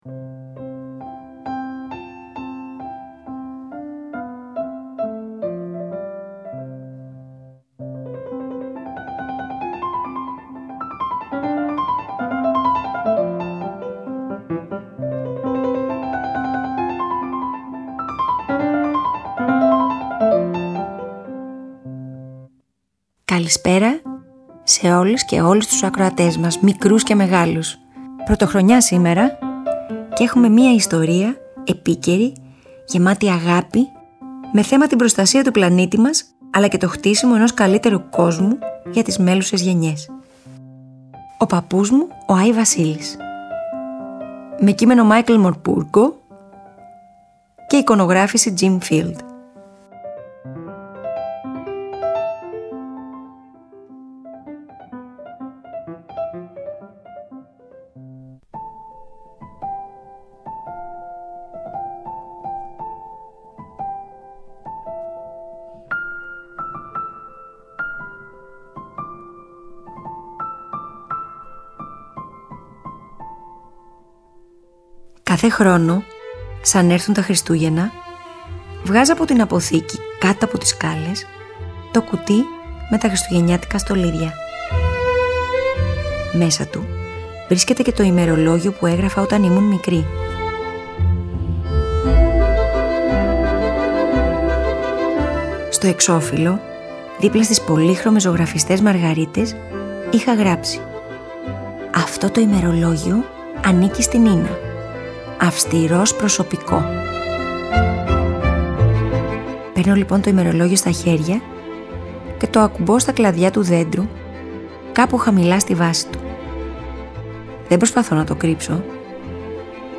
Αφήγηση
Μουσικές επιλογές